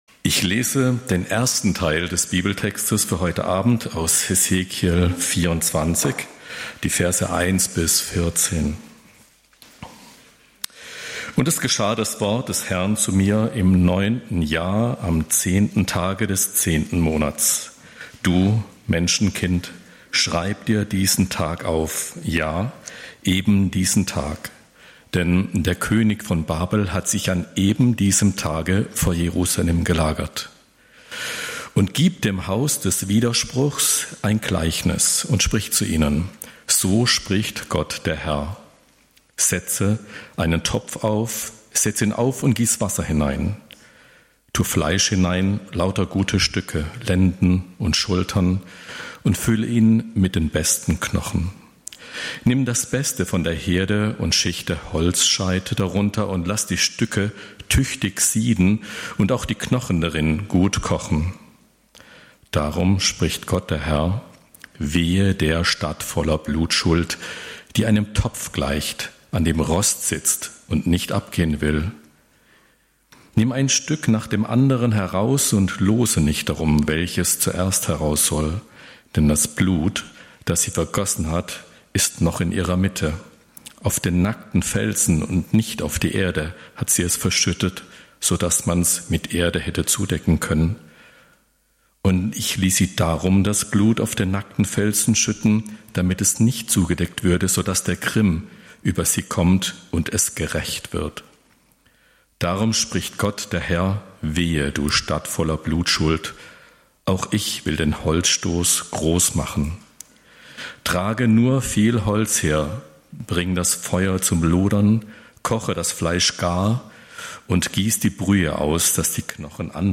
Hesekiel - 18: Die Hesekiel-Zäsur (Hes. 24) - Bibelstunde